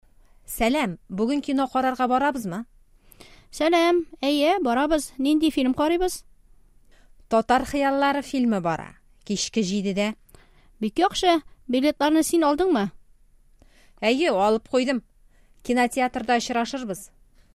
Диалог 2: Кинога бару турында килешү – Договоренность о походе в кино